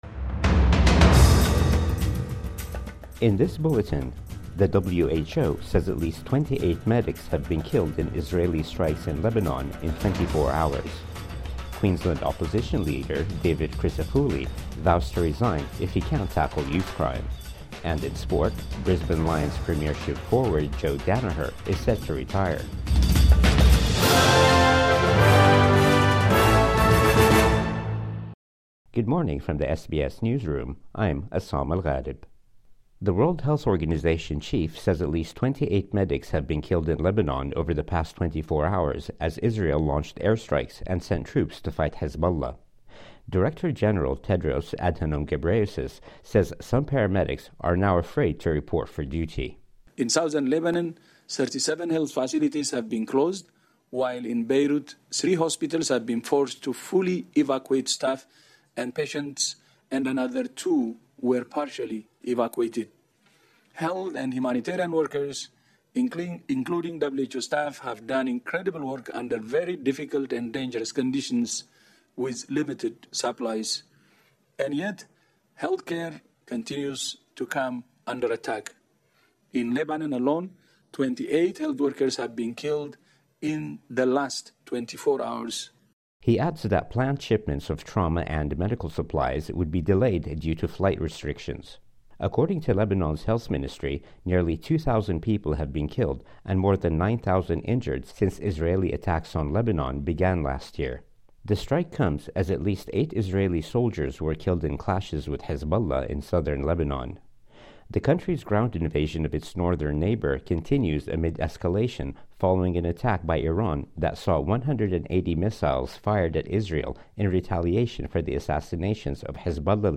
Morning News Bulletin 4 October 2024